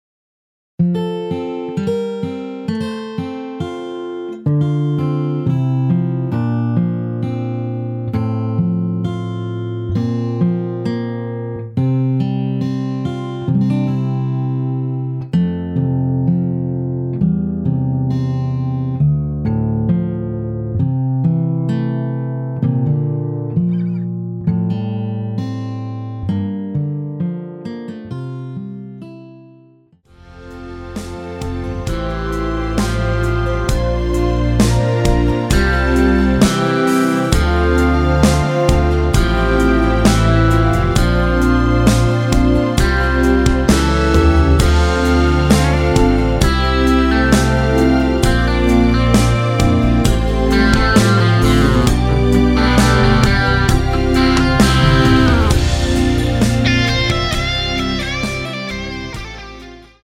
원키에서 (-1) 내린 MR 입니다.
앞부분30초, 뒷부분30초씩 편집해서 올려 드리고 있습니다.
중간에 음이 끈어지고 다시 나오는 이유는